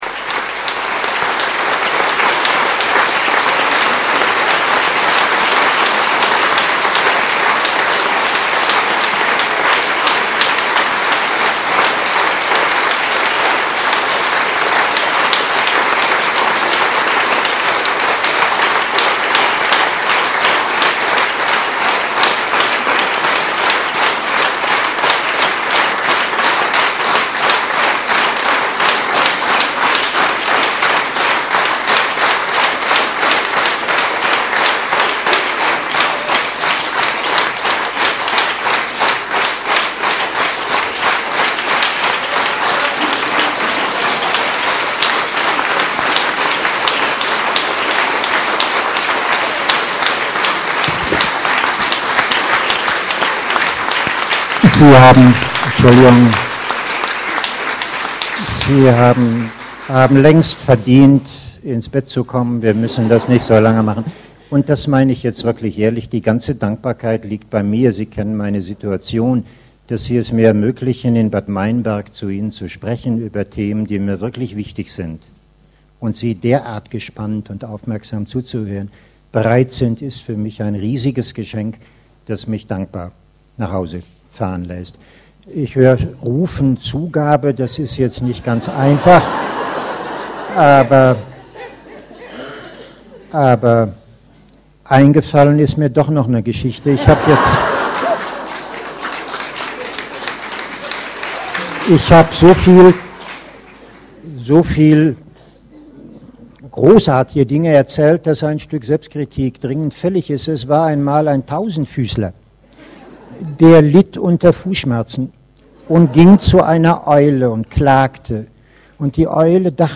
Dies war die \"Zugabe\", nach den minutenlangen \"Standing
Ovations\" am Ende seines Vortrags am Yoga Kongress im haus Yoga